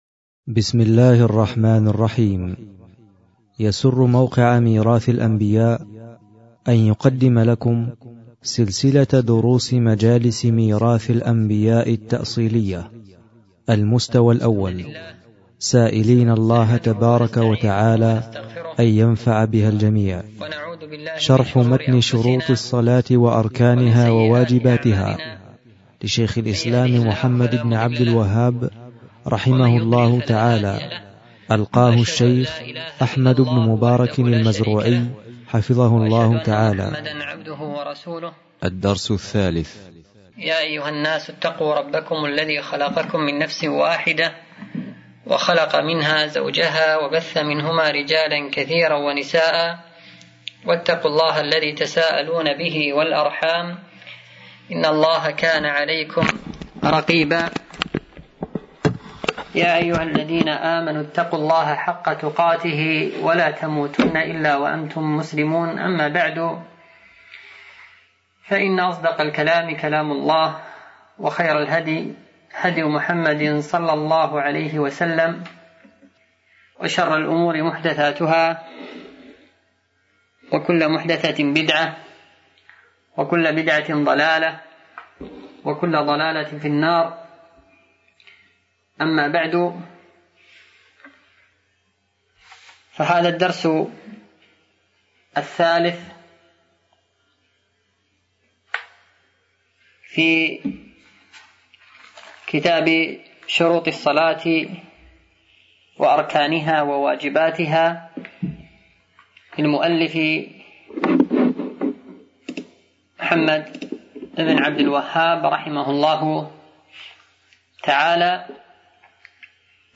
شروط الصلاة وأركانها وواجباتها - الدرس الثالث